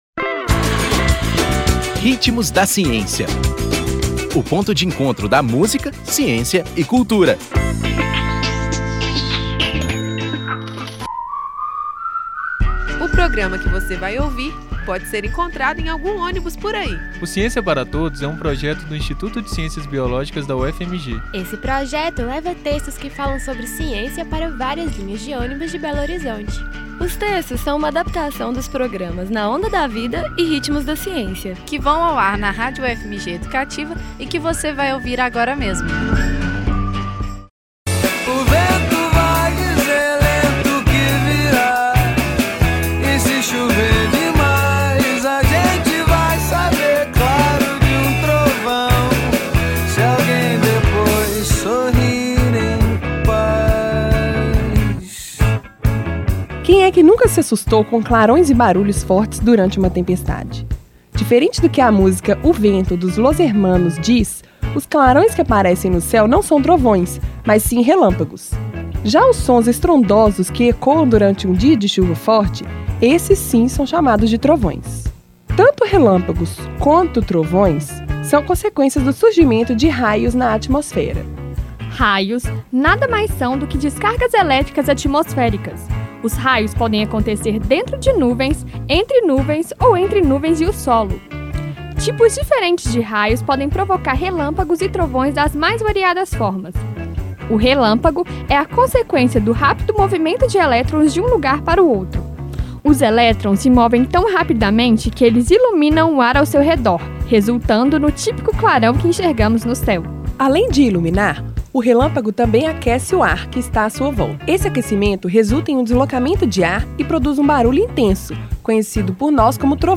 Nome da música: O vento
Intérprete: Los Hermanos